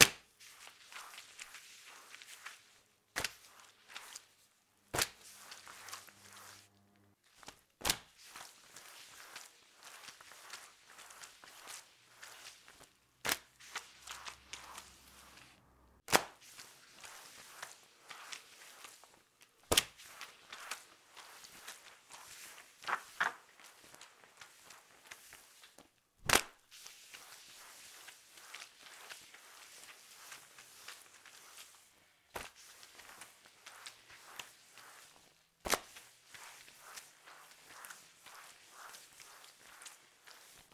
Mopping_SFX.wav